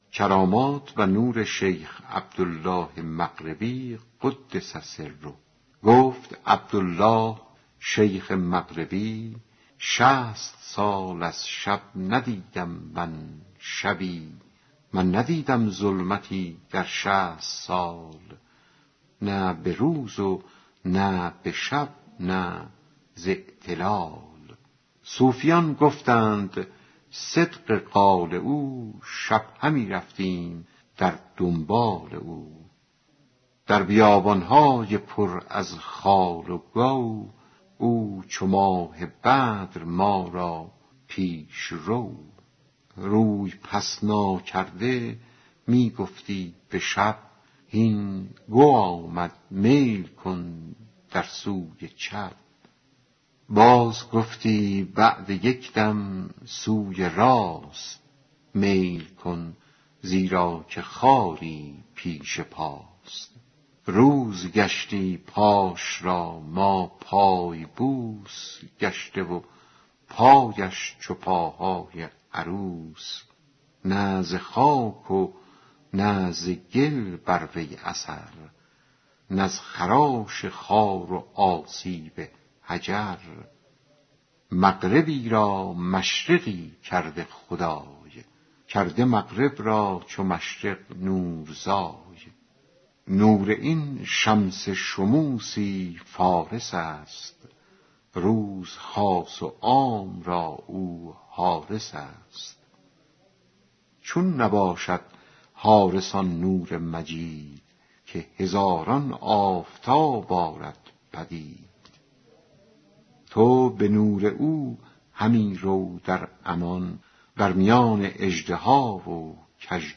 دکلمه قصه کرامات و نور شیخ عبدالله محمد مغربی